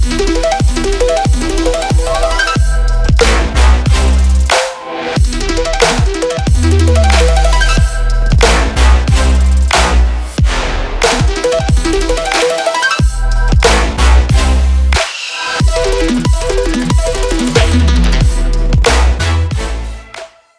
• Качество: 320, Stereo
громкие
Electronic
EDM
без слов
Electronica
Trap
Bass